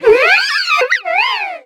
Cri de Lockpin dans Pokémon X et Y.